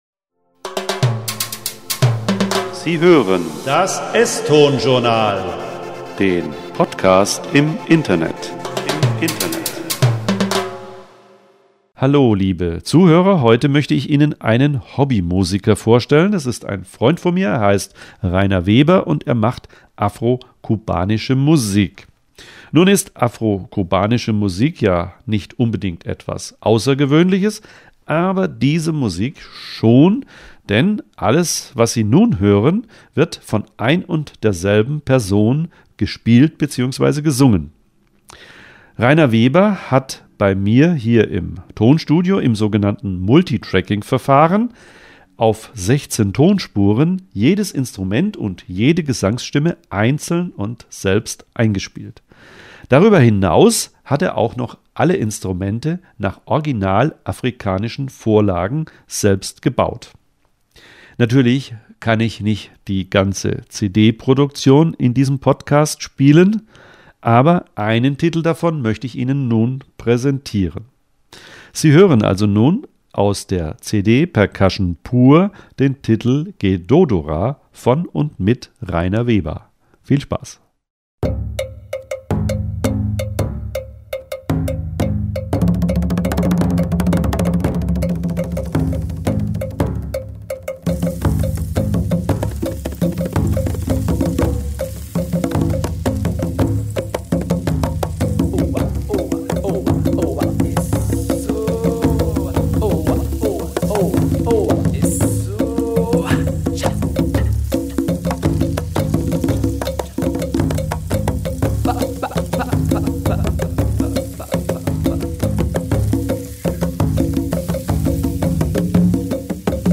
Percussion pur
Afrocubanische Musik
auf 16 Tonspuren.